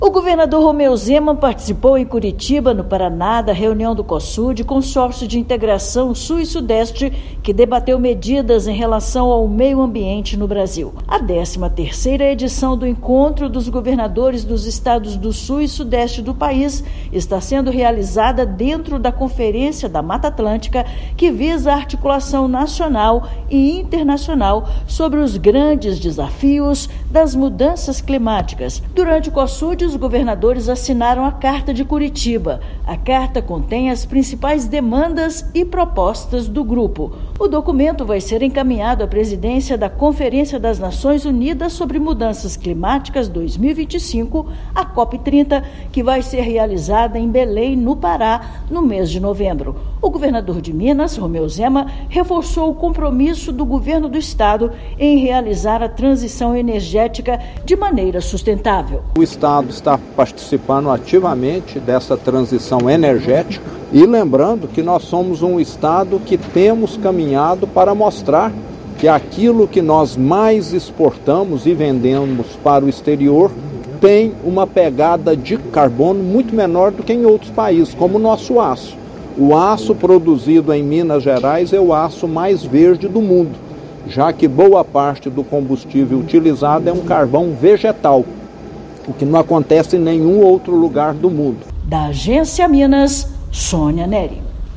Encontro realizado na capital do Paraná definiu as principais demandas e propostas para a preservação, sobretudo da Mata Atlântica, que serão apresentadas para a presidência da COP-30. Ouça matéria de rádio.